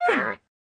Minecraft Version Minecraft Version snapshot Latest Release | Latest Snapshot snapshot / assets / minecraft / sounds / mob / panda / cant_breed2.ogg Compare With Compare With Latest Release | Latest Snapshot
cant_breed2.ogg